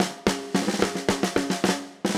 Index of /musicradar/80s-heat-samples/110bpm
AM_MiliSnareA_110-01.wav